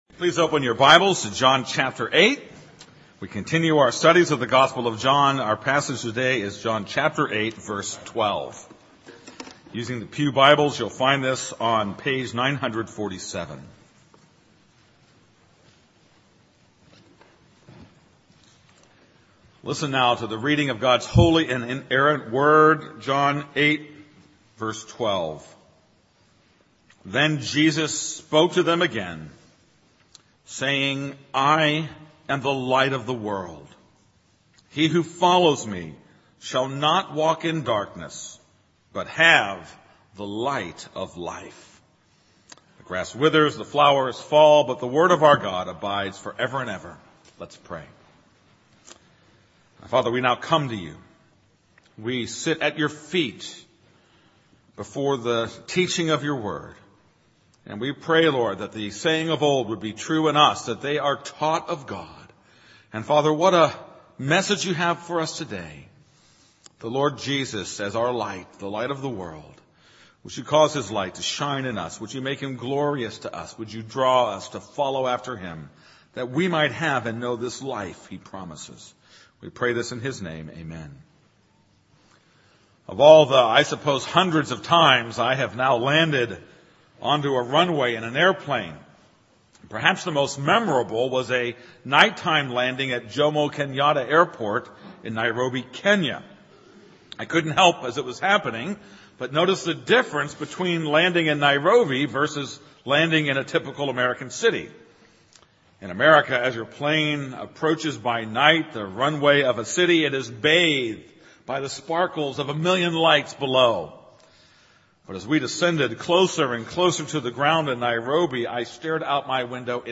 This is a sermon on John 8:12.